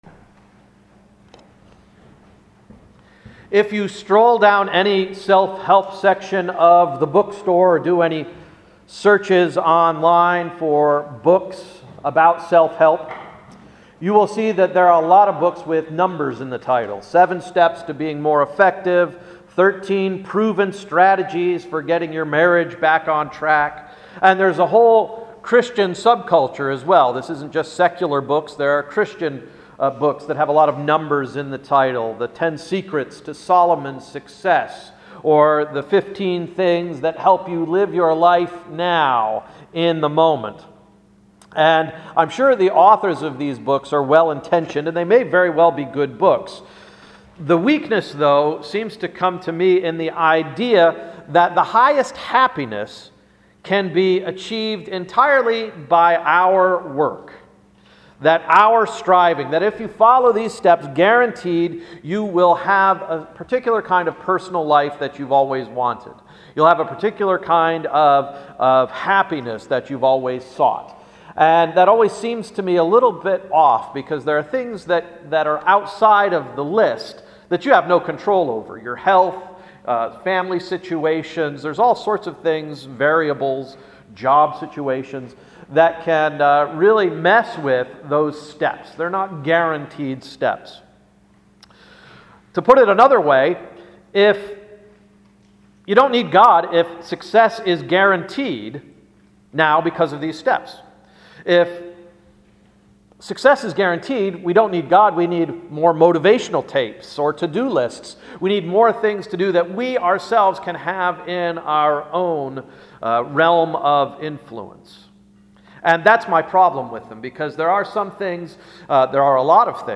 Sermon of April 21, 2013–“The Beautiful Temple”